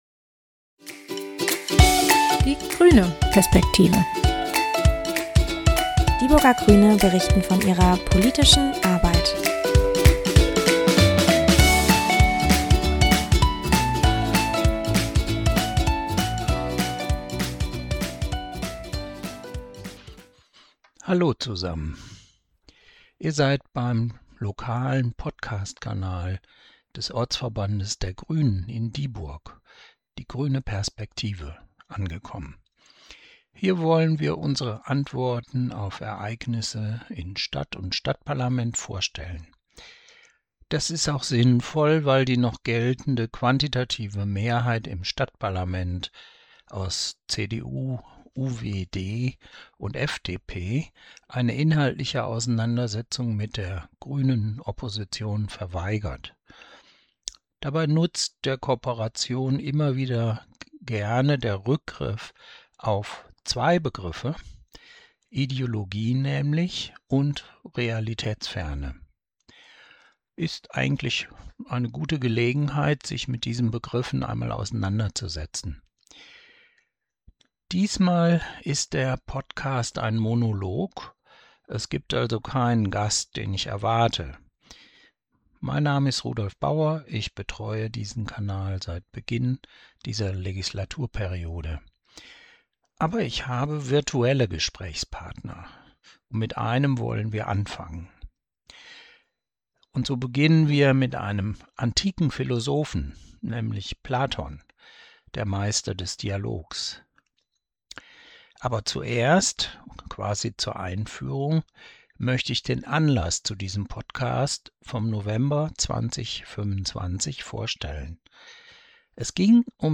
Diesmal als Monolog zur politischen Philosophie der Neuzeit. Anlaß ist die Haushaltsdiskussion im Stadtparlament Dieburgs 2025